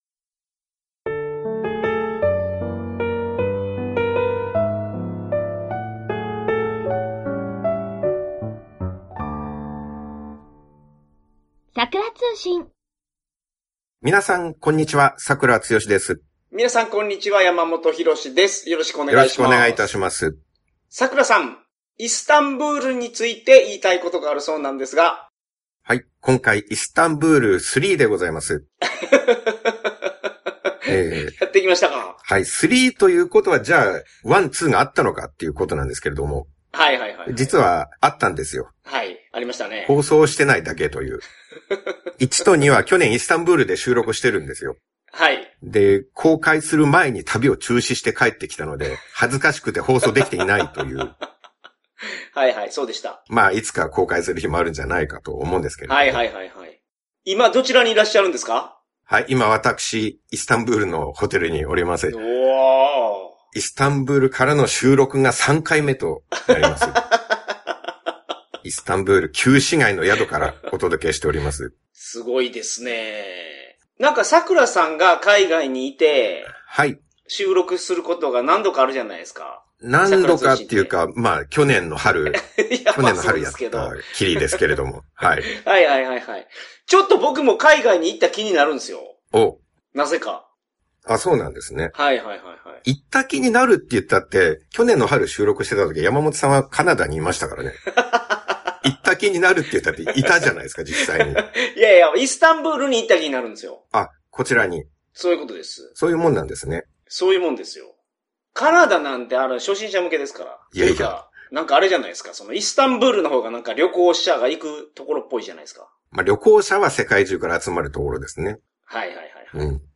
いよいよ海外からの収録を初公開です！！